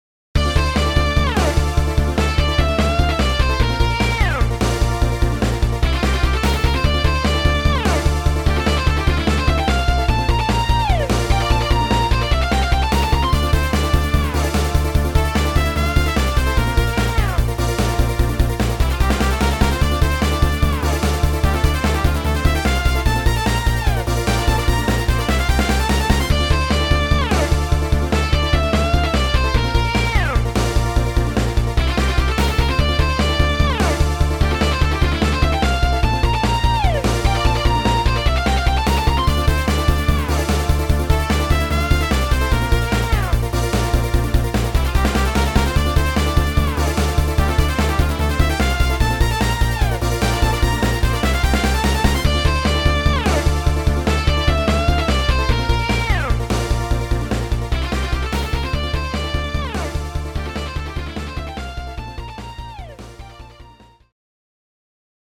出だしは思いっきり世紀末なイメージ。